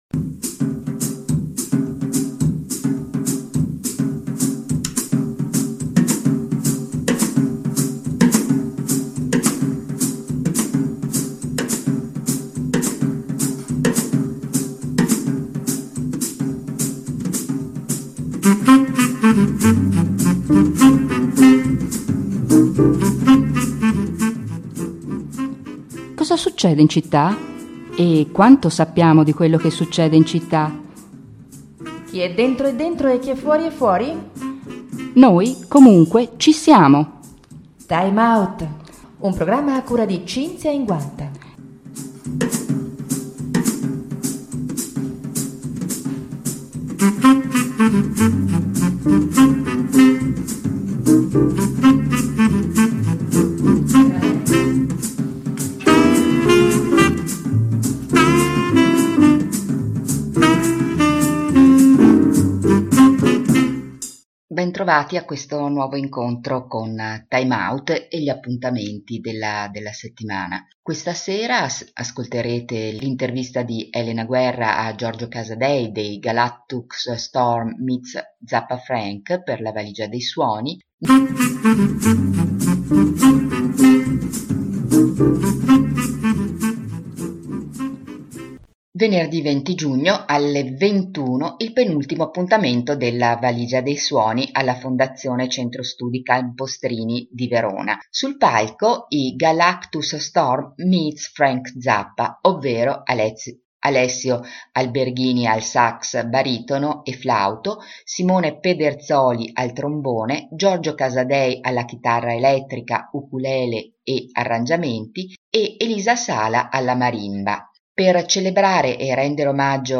Intervista all'interno del programma Time Out di Radio Popolare Verona.